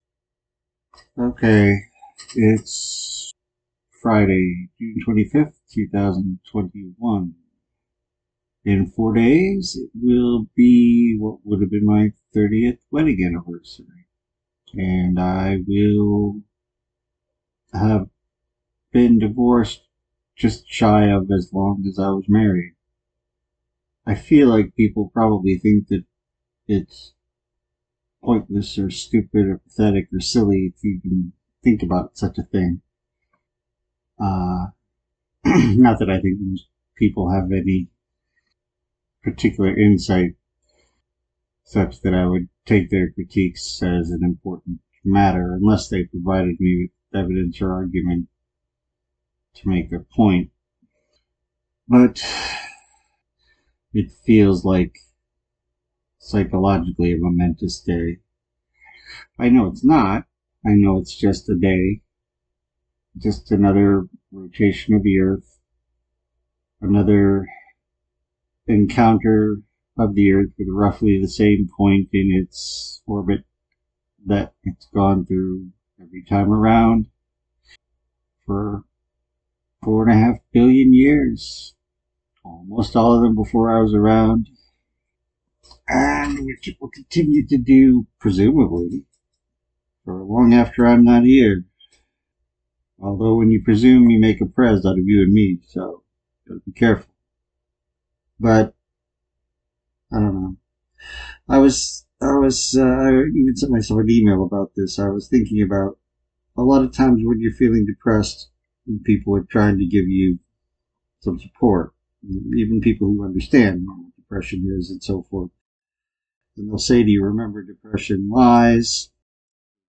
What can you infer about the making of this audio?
Apologies, the sound quality it not quite ideal. I didn’t realize that until after I’d recorded, and I did a little editing to try to improve it, but it’s a bit bland.